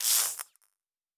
pgs/Assets/Audio/Sci-Fi Sounds/Electric/Spark 05.wav at master
Spark 05.wav